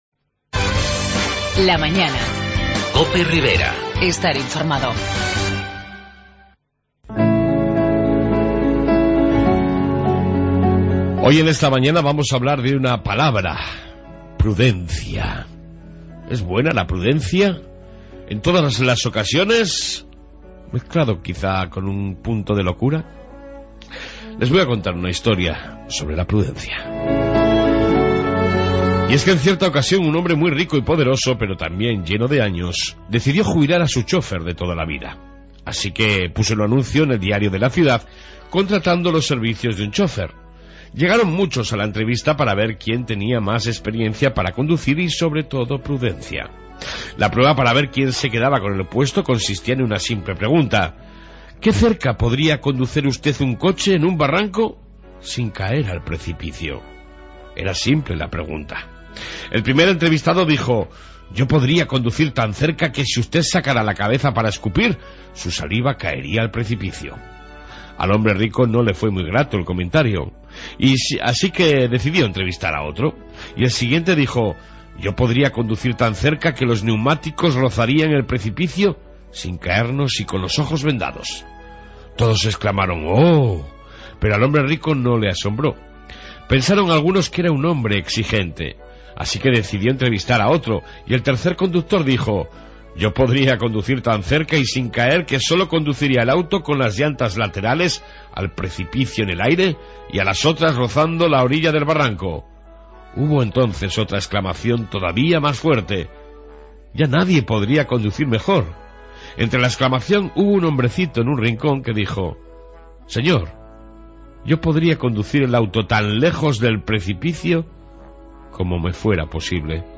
En esta 1ª parte Reflexión diaria, Informe Policía Municipal y amplia entrevista